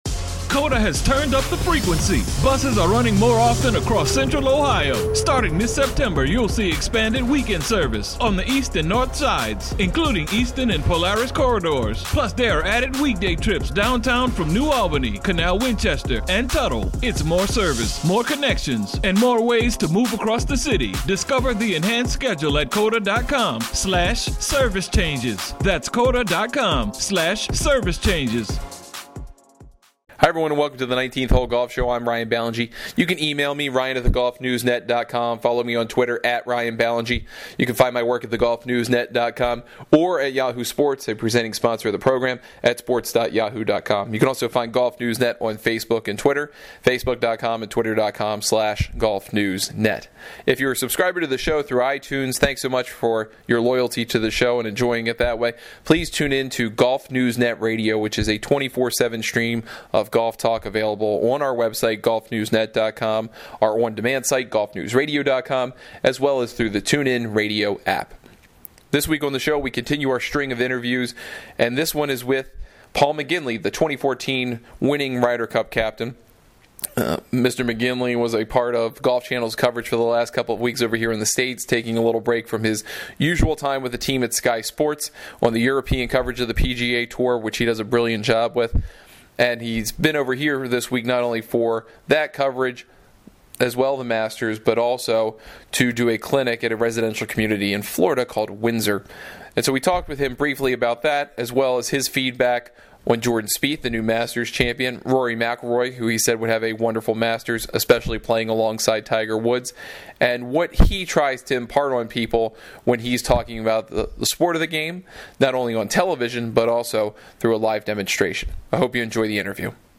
Paul McGinley, winning 2014 Ryder Cup captain, joined the show to talk about Jordan Spieth's approach to the Masters and how he took control of the event. The Irishman also shared his views on how Rory McIlroy turned around his Masters in the final 45 holes, as well how Justin Rose did in standing toe-to-toe with Spieth.